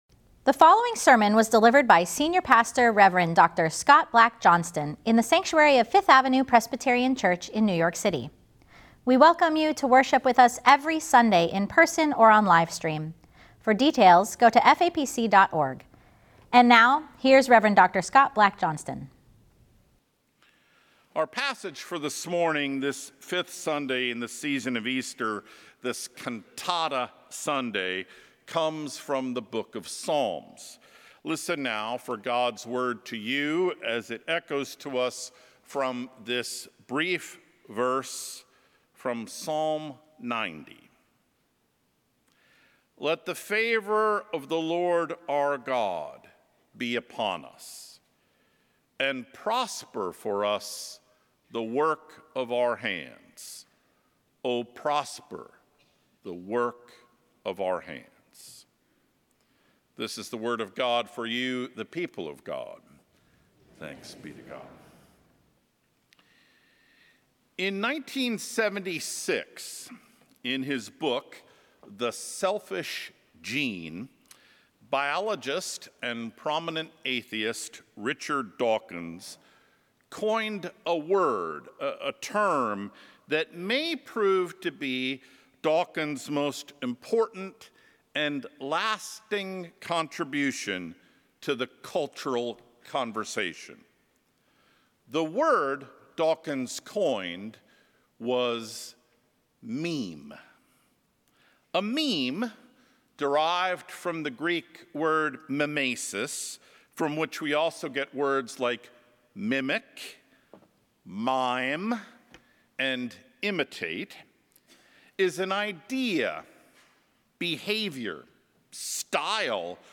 Sermons at FAPC
Weekly_podcast_Cantata_Sunday_5-18-25.mp3